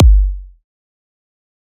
EDM Kick 15.wav